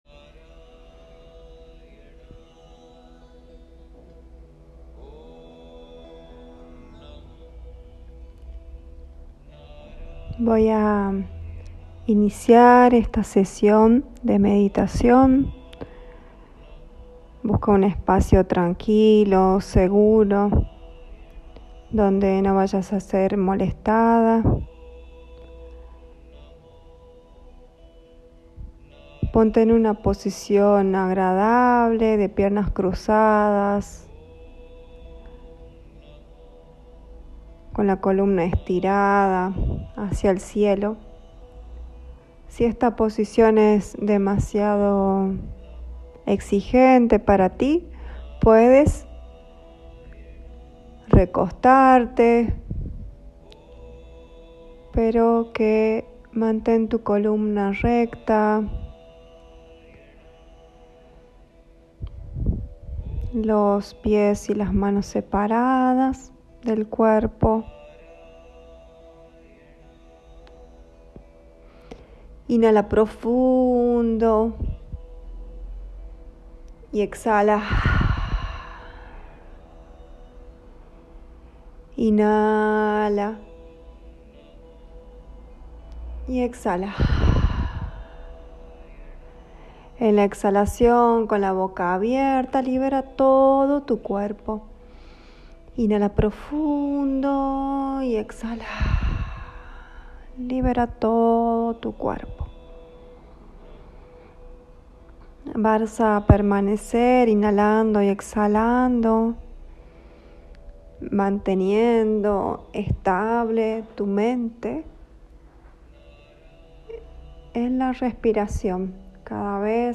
Meditaciones guiada para conectar con tu corazón para escuchar ahora !